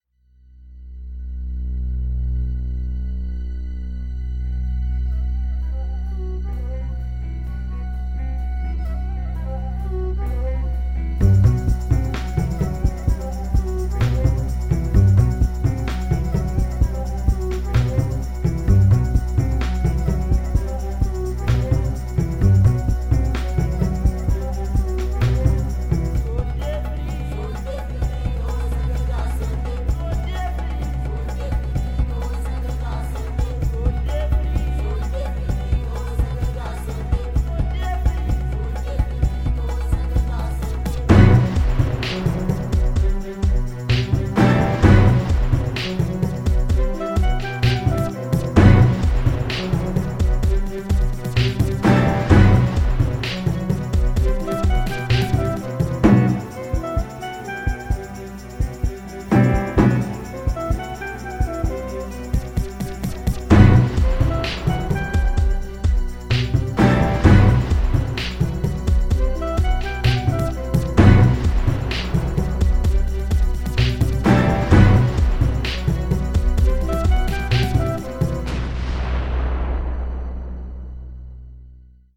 Audio (Title menu music)